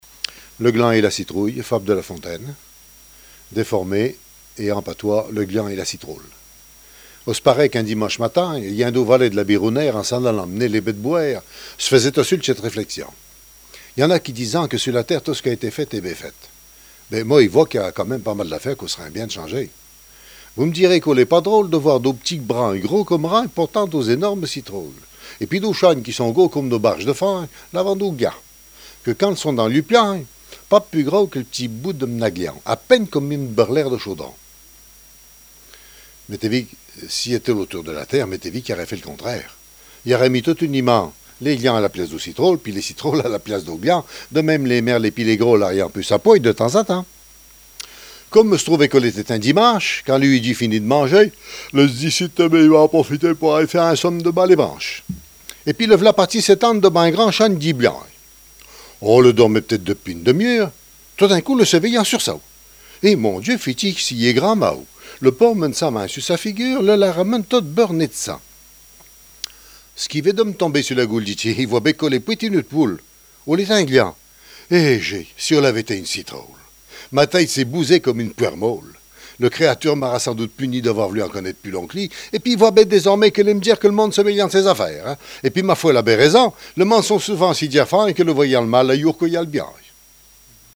Langue Maraîchin
Genre fable
Catégorie Récit